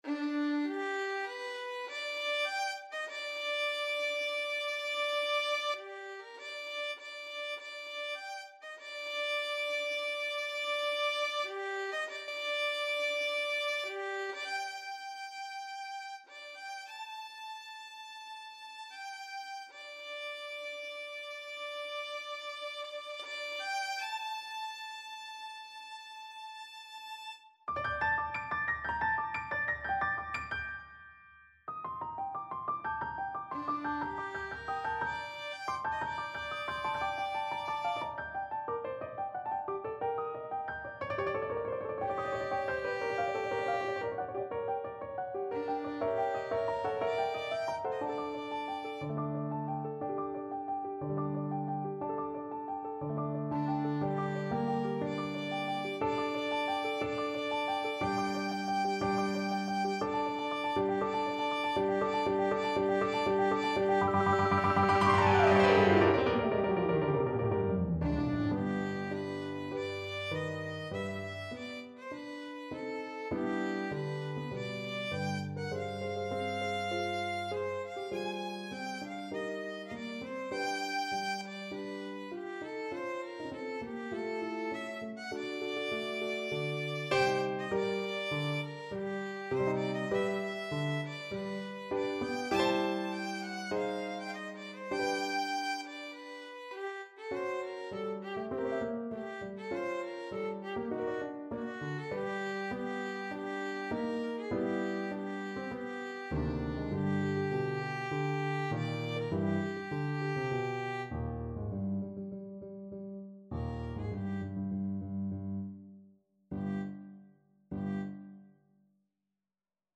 Classical Mahler, Gustav Symphony No. 7, Nachtmusik (Theme from Castrol GTX Advert) Violin version
Violin
G major (Sounding Pitch) (View more G major Music for Violin )
Allegro moderato (View more music marked Allegro)
4/4 (View more 4/4 Music)
Classical (View more Classical Violin Music)